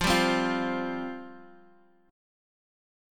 Fsus2 chord